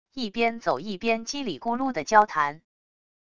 一边走一边叽里咕噜的交谈wav音频